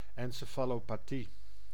Ääntäminen
IPA: ɛnsefalopaˈtiː